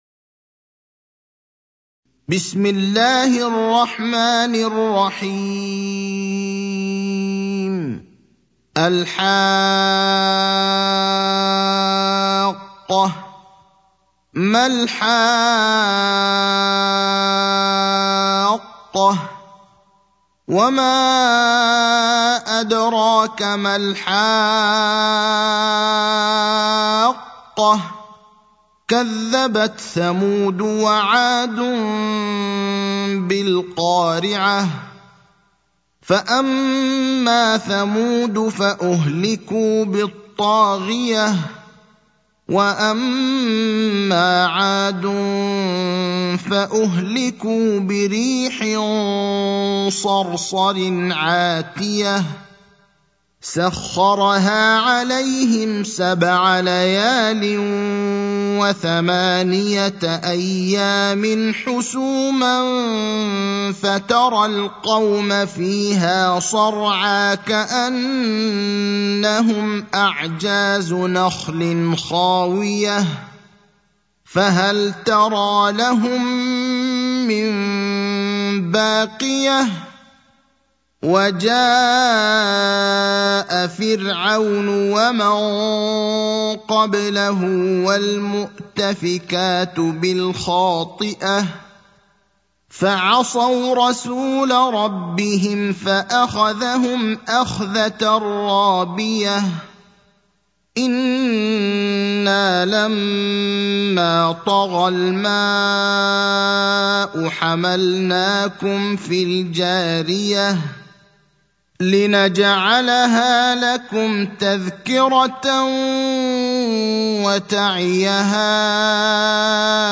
المكان: المسجد النبوي الشيخ: فضيلة الشيخ إبراهيم الأخضر فضيلة الشيخ إبراهيم الأخضر الحاقة (69)